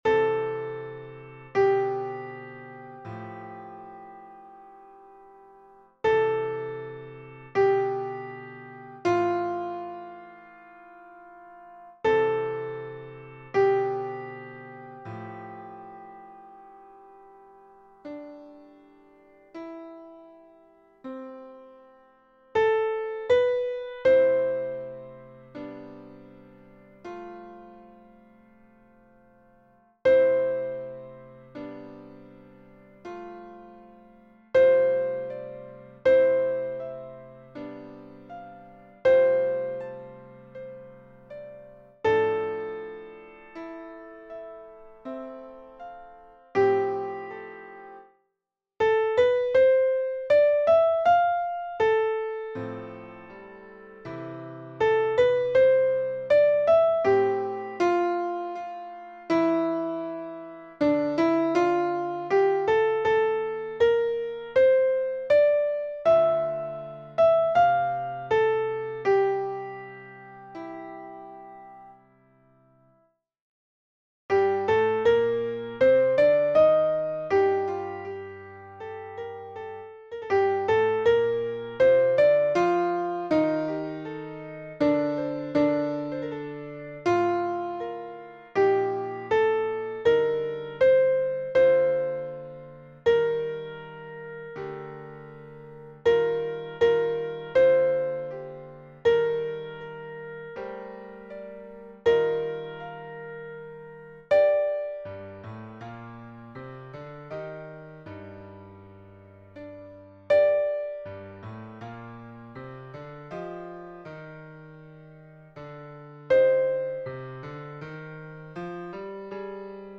Mezzo Soprano (version piano)